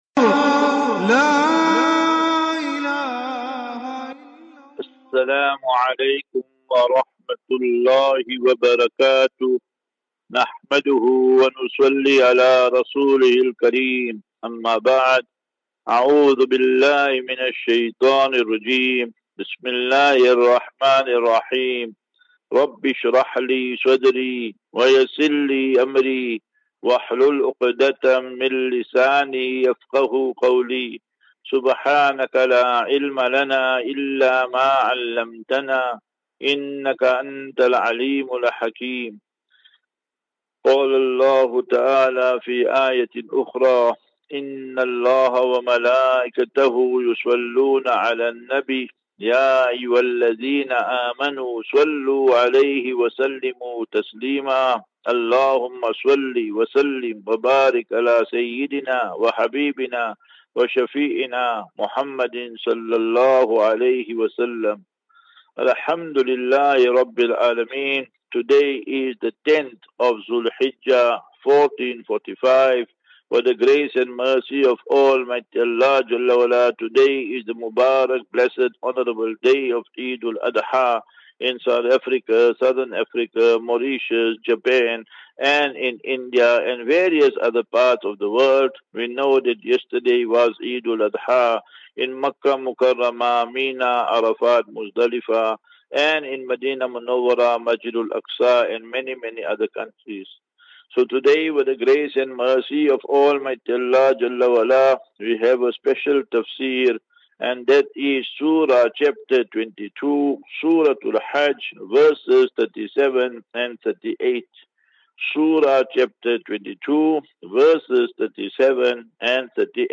Assafinatu - Illal - Jannah. Eid QnA